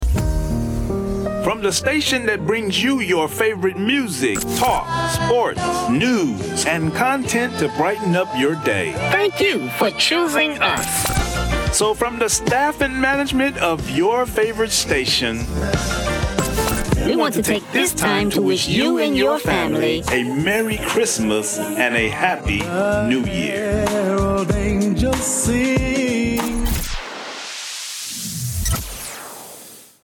Merry Christmas Radio Drop [FREE]
Pay now OR Add to cart Category: Radio Imaging Audio